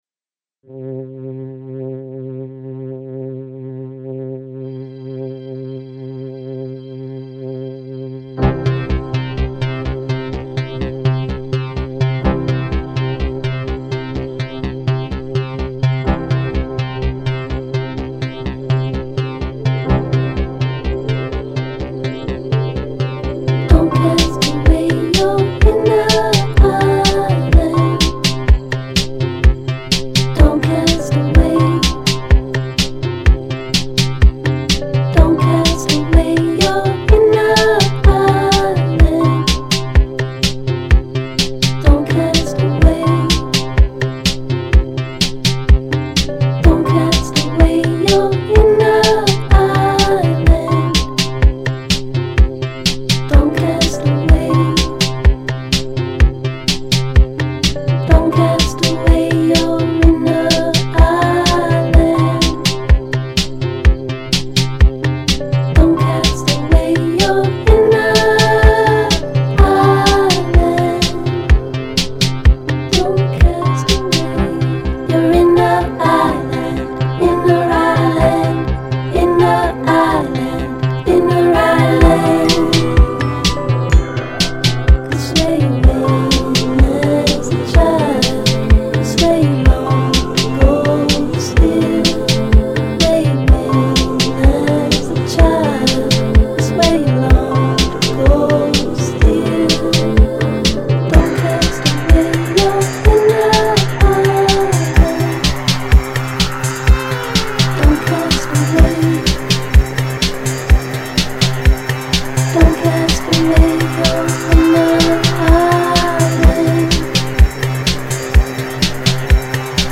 e l'ha remixato in direzione electropop.